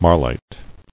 [mar·lite]